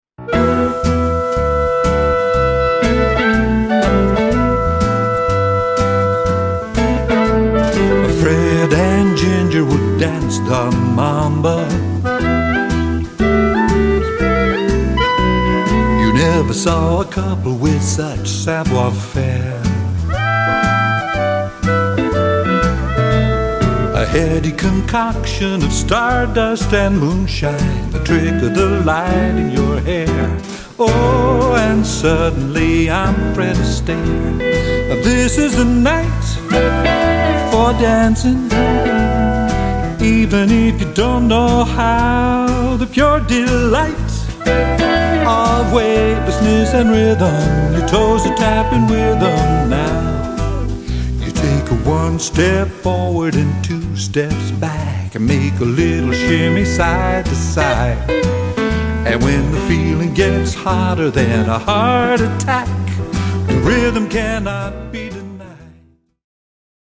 Here are clips of a few early samples, the first The Night for Dancin’ is 90% complete, though the vocal will be re-recorded before the official release.